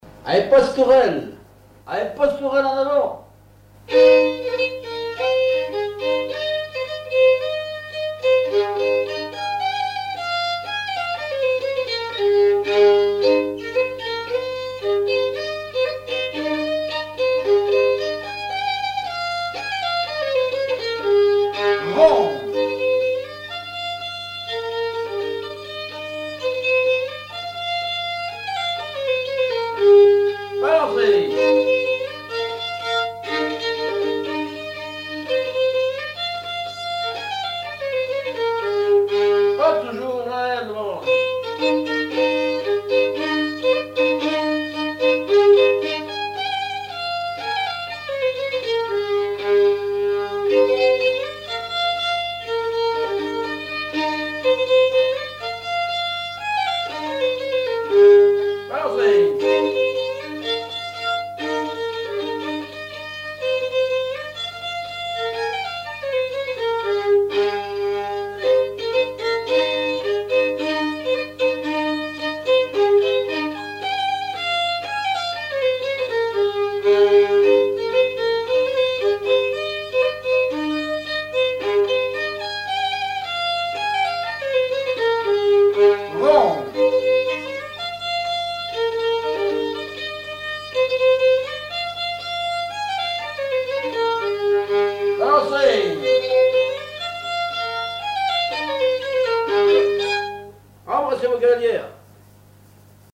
danse : quadrille : pastourelle
Auto-enregistrement
Pièce musicale inédite